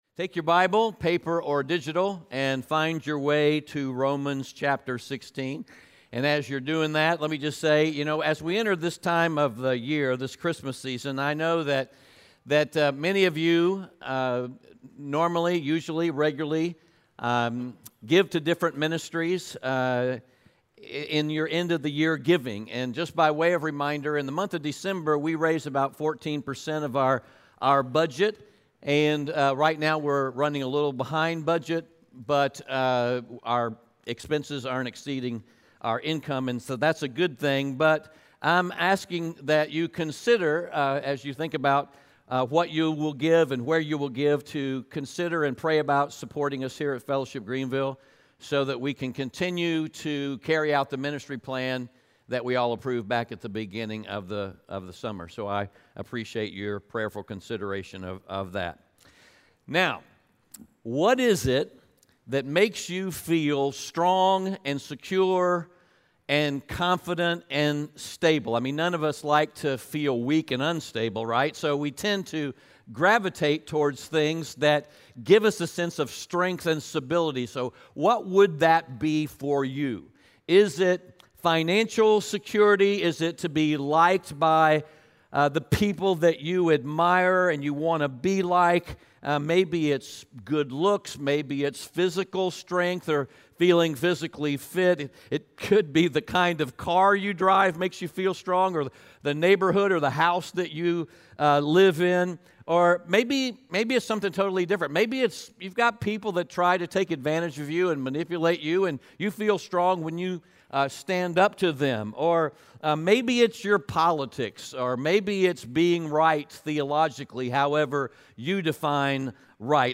Romans 16:25-27 Audio Sermon Notes (PDF) Ask a Question *We are a church located in Greenville, South Carolina.